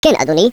Add hebrew voices